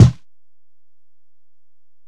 Kick (31).wav